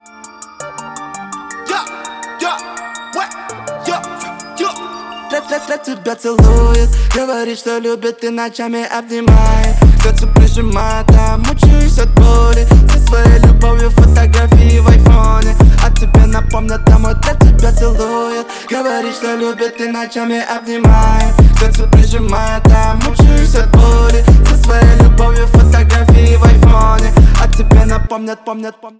мужской вокал
лирика
мощные басы